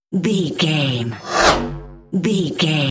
Pass by sci fi fast
Sound Effects
Fast
futuristic
intense
car
vehicle